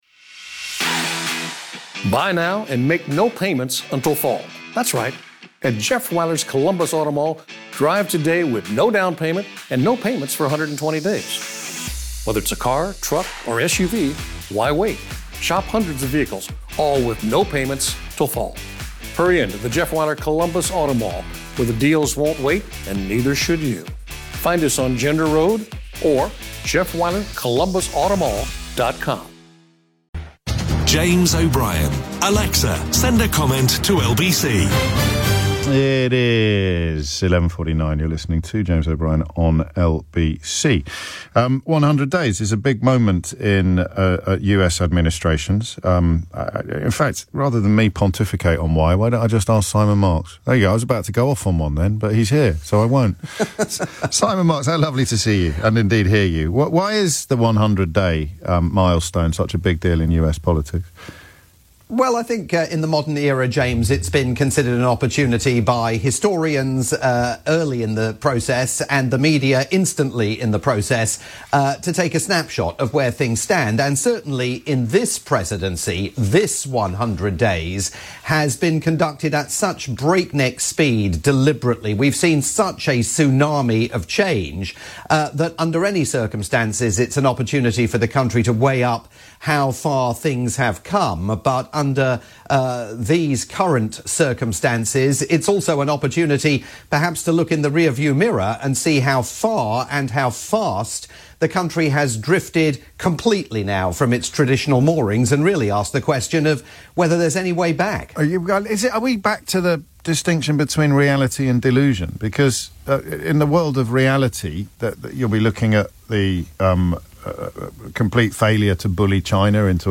live chat with James O'Brien on the UK's LBC.